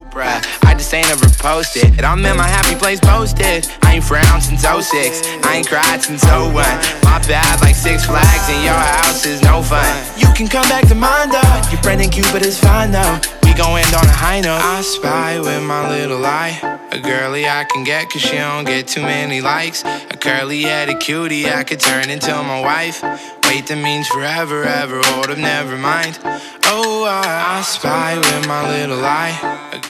• Hip-Hop